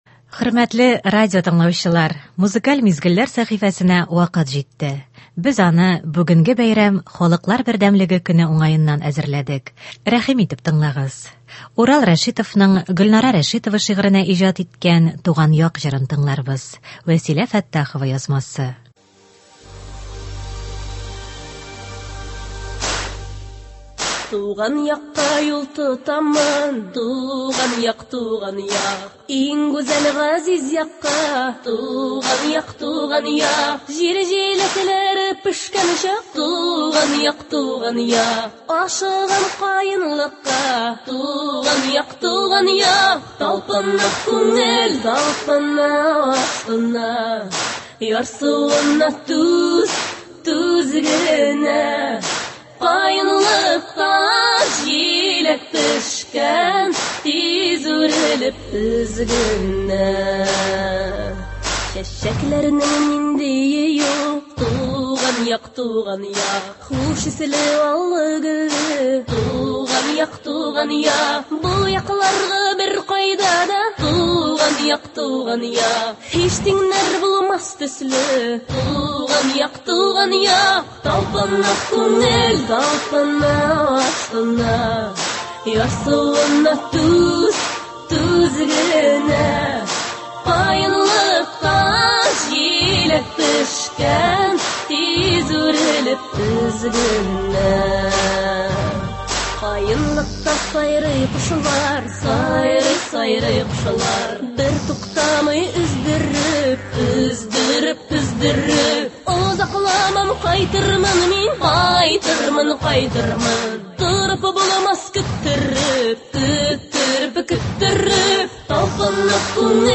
Иртәнге концерт.